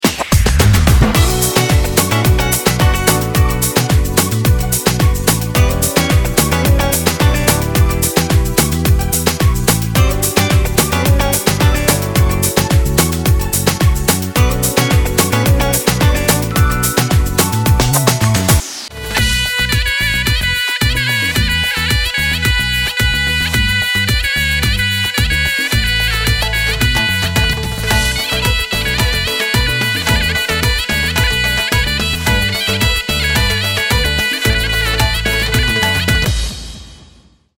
Давно ищу попсовый бас.
Очень нравится этот тугой, отлично читаемый бас (POPBass).